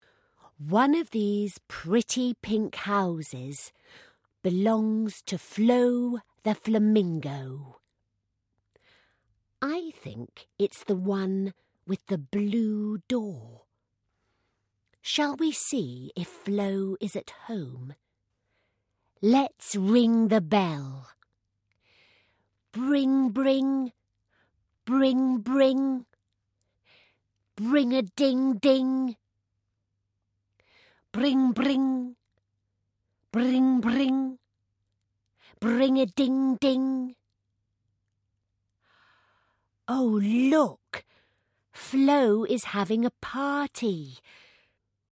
USB stick with: 50 page bumper resource pack – includes guidance, activities, lolly stick puppets, colouring sheets and MP3 audio book read by Sophie Thompson